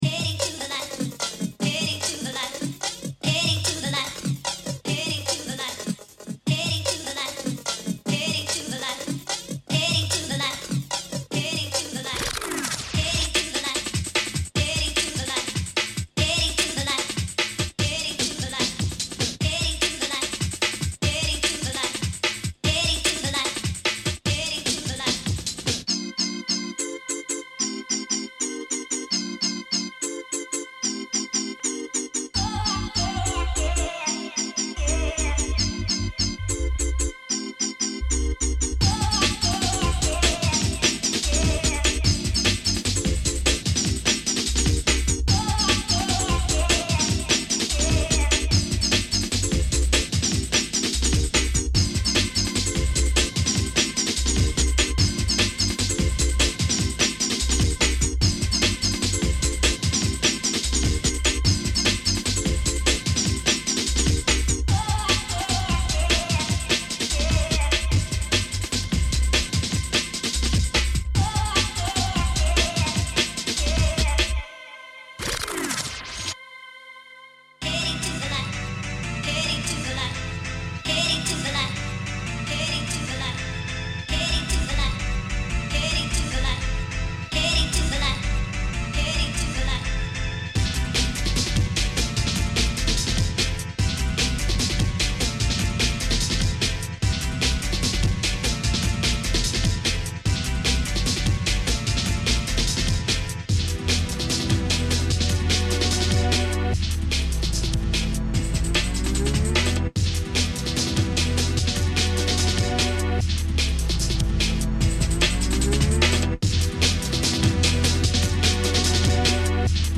Drum & Bass mixes